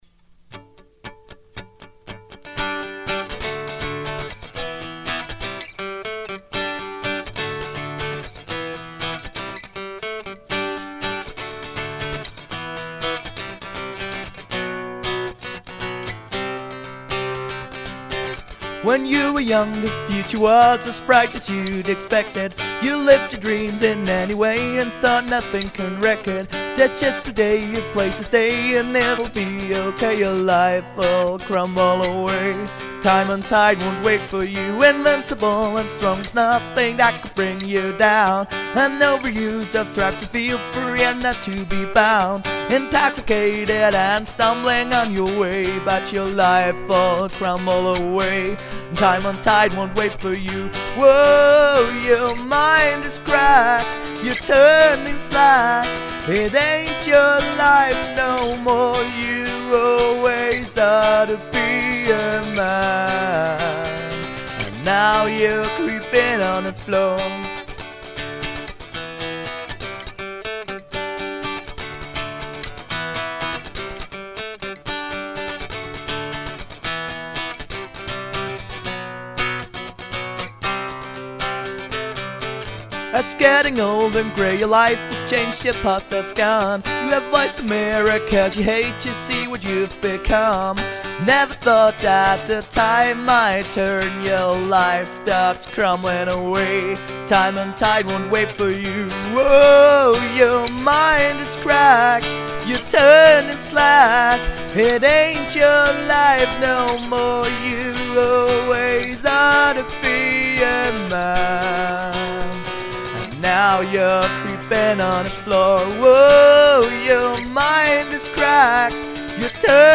You should add a little more to the recording during the verse and chorus. Maybe some drums and bass or just some more guitar track.